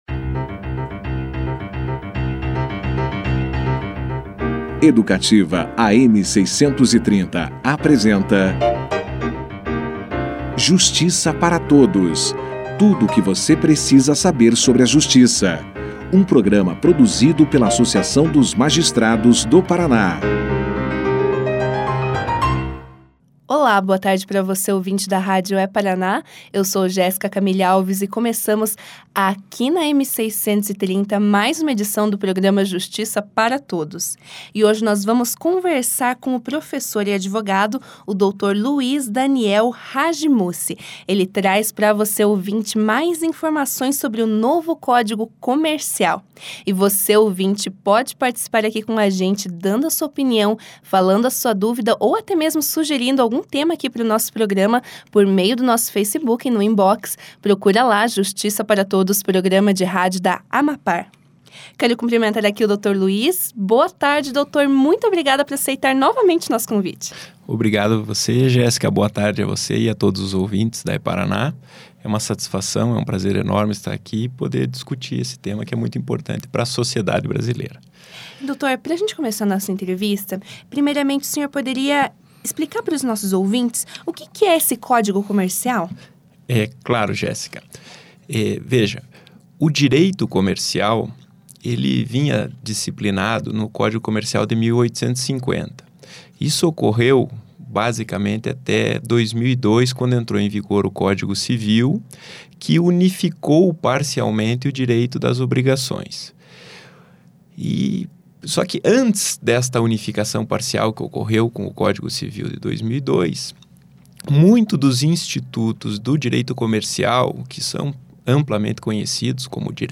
Professor fala sobre Código Comercial no Justiça para Todos